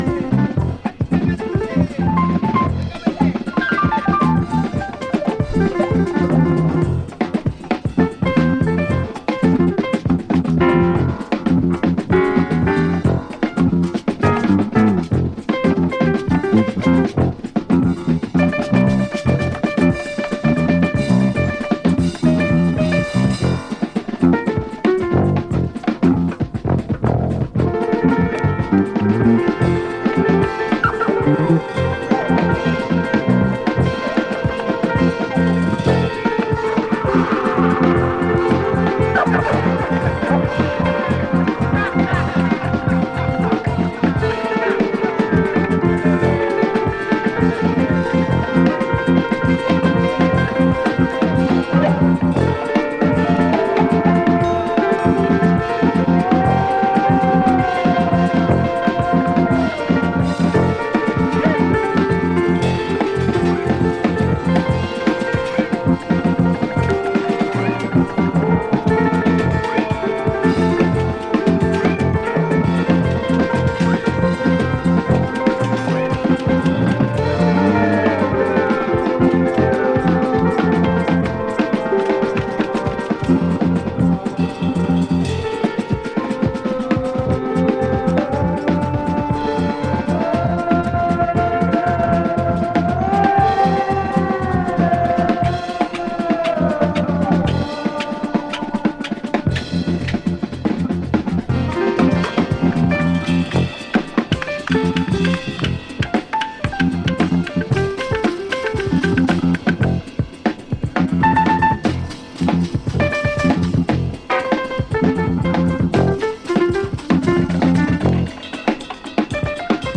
Jazz
Experimental